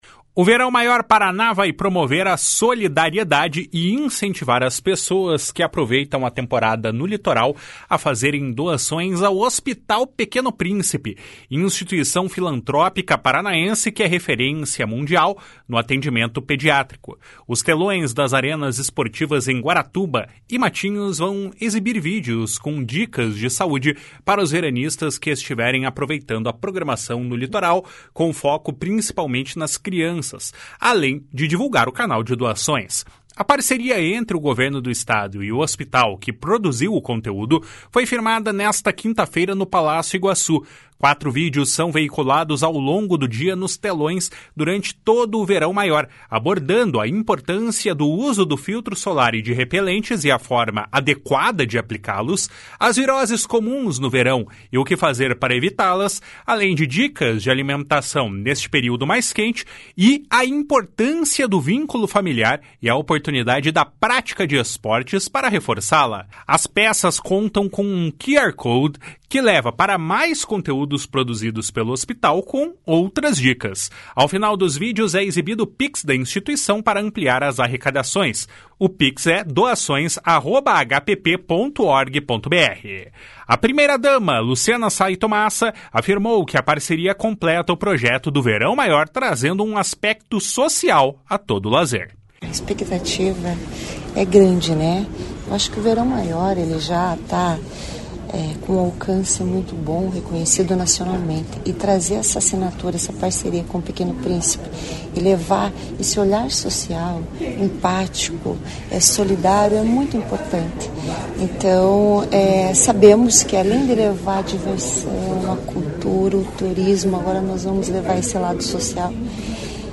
O secretário estadual da Saúde, Beto Preto, afirmou que a parceria aproveita do Verão Maior e os momentos de lazer para a conscientização, usufruindo da credibilidade do hospital. // SONORA BETO PRETO //